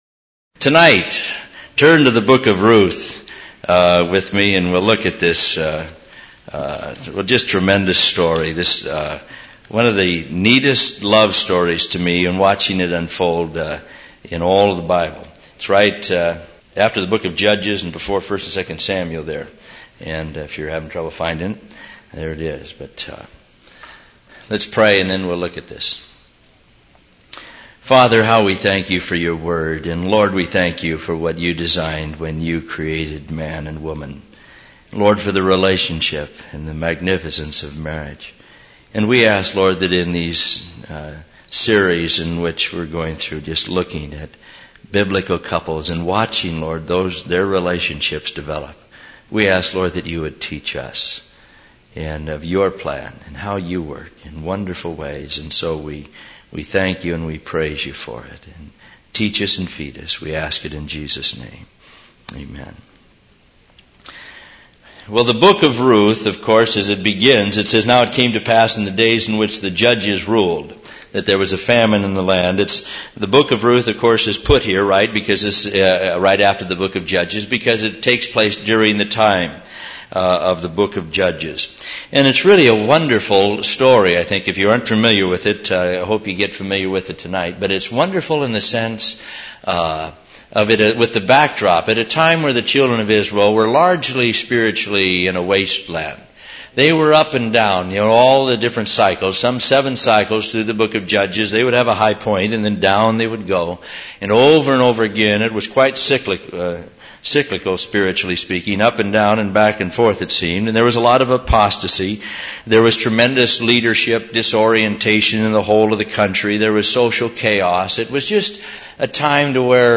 In this sermon, the preacher discusses the book of Ruth and its significance in the context of the spiritual state of the children of Israel during that time. The book portrays a cyclical pattern of spiritual ups and downs, with periods of apostasy and social chaos. The preacher highlights the romance between Ruth and Boaz as a godly and wonderful example.